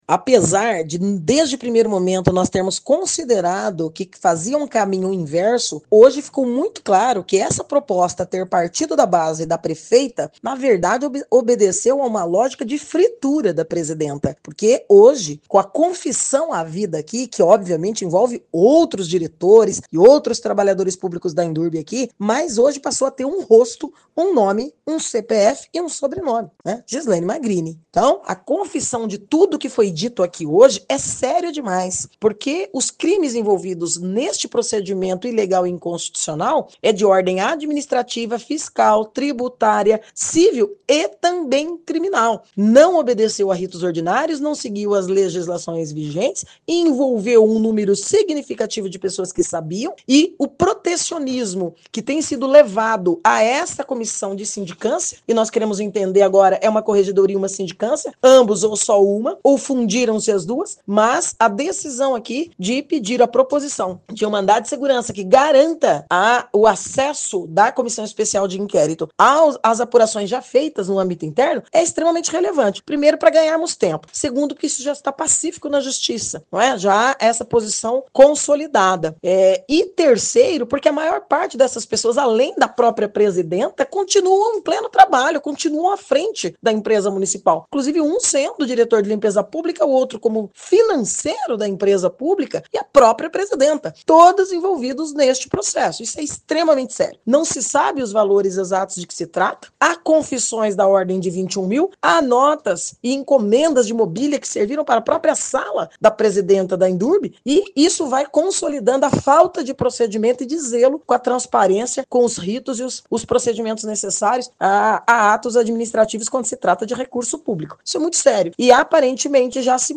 A vereadora Estela Almagro, membro da CEI, fez um resumo da reunião de hoje e falou sobre os próximos passos.